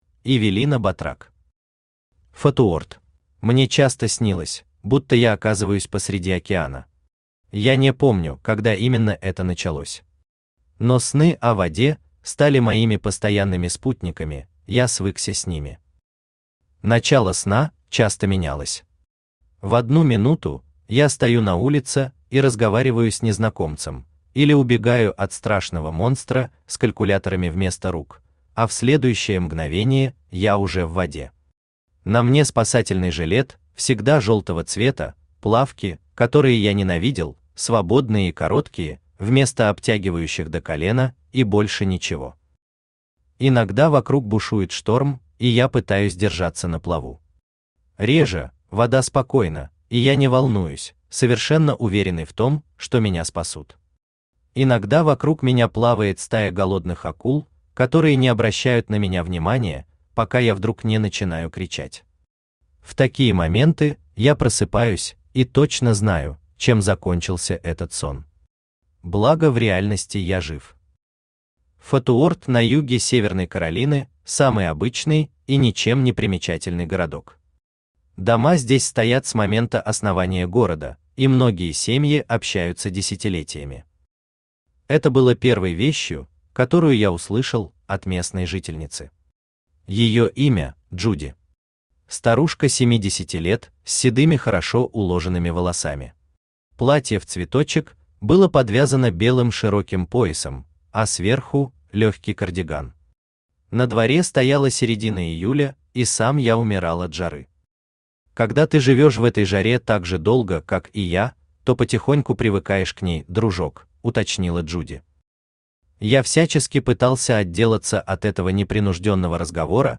Аудиокнига Фотуорт | Библиотека аудиокниг
Aудиокнига Фотуорт Автор Эвелина Батрак Читает аудиокнигу Авточтец ЛитРес.